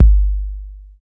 KIK 808 K 4.wav